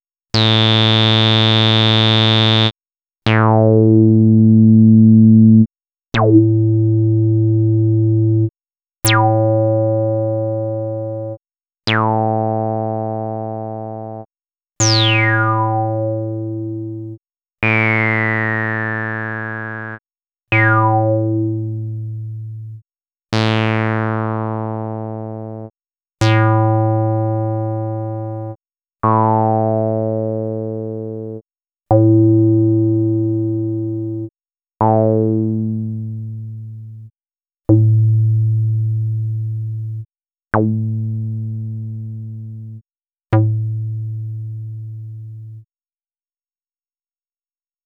10_AcidBass.wav